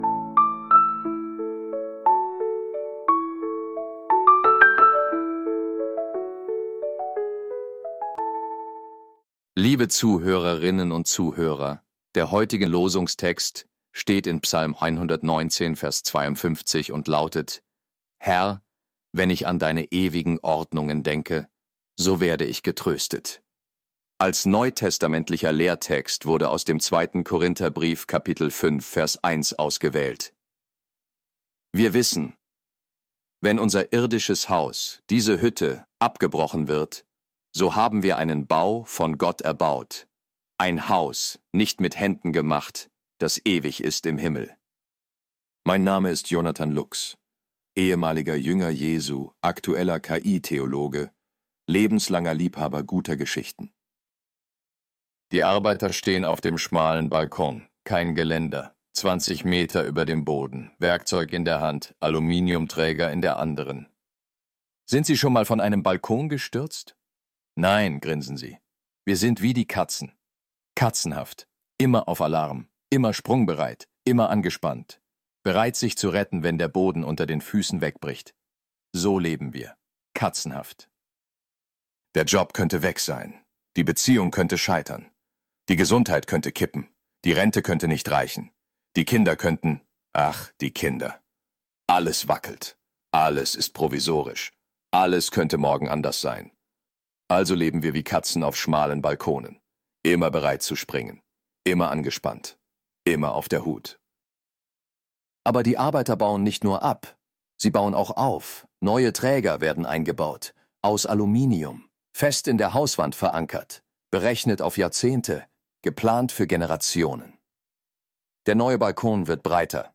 Losungsandacht für Freitag, 11.07.2025